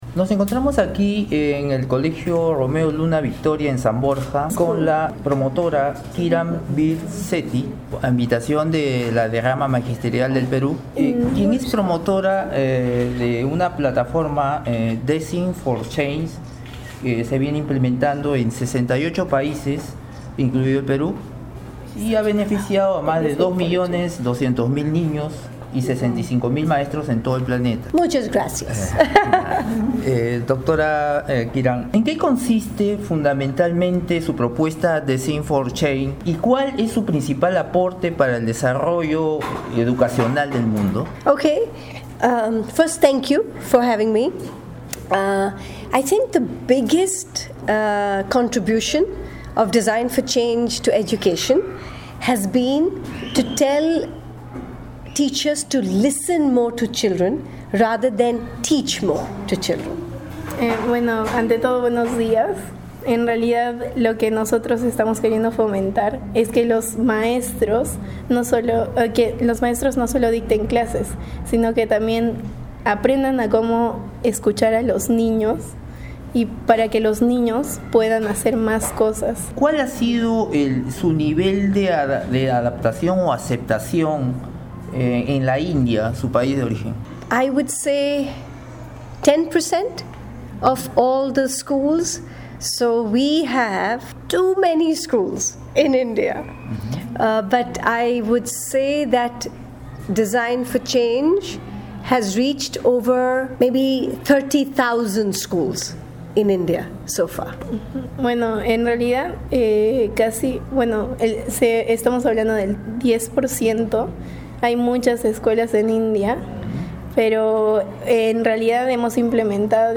A continuación, la entrevista que, en exclusiva, concedió la educadora Kiran Bir Sethi a Red de Comunicación Regional (RCR).